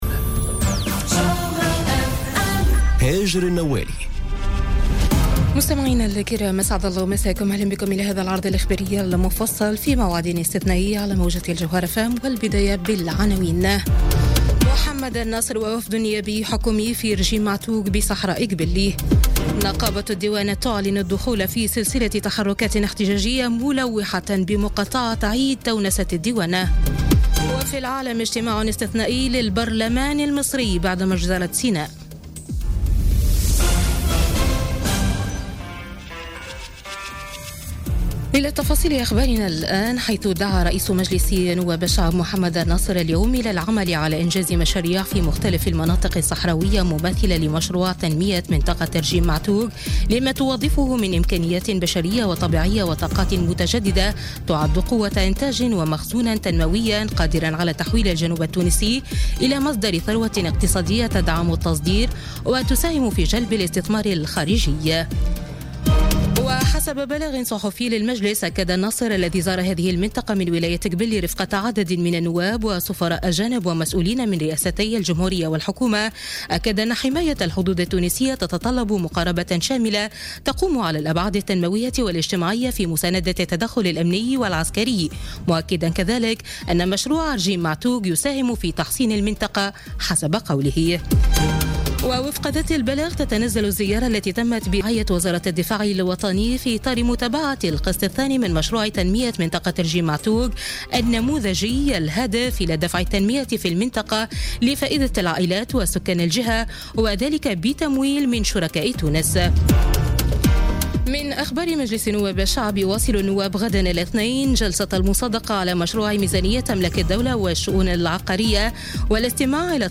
نشرة أخبار السابعة مساء ليوم الأحد 26 نوفمبر 2017